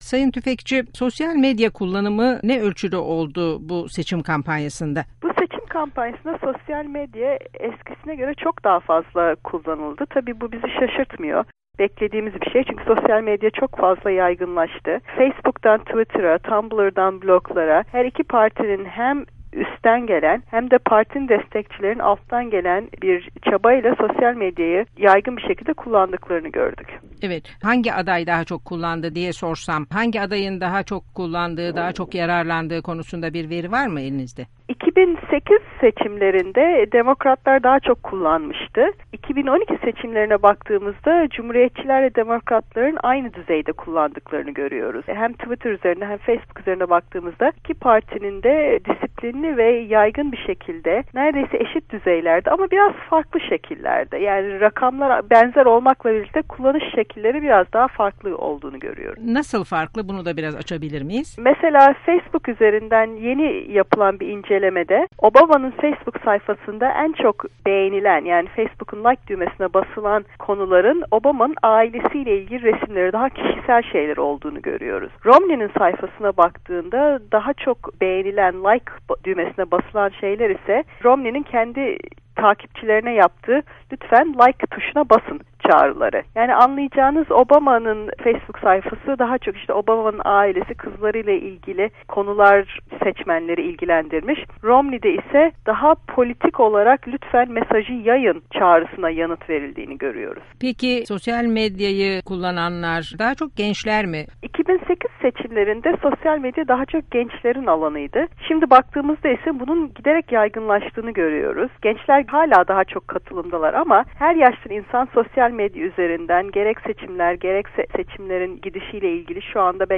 Zeynep Tüfekçi ile Söyleşi